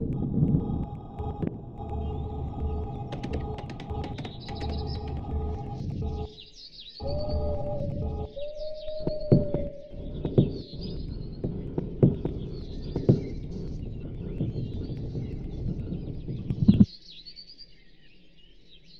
animals-on-land.mp3